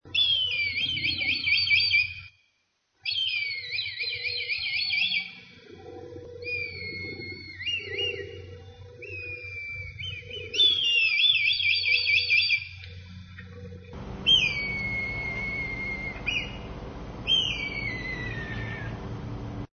Click on the Kite to hear its distinctive call
kitescall.mp3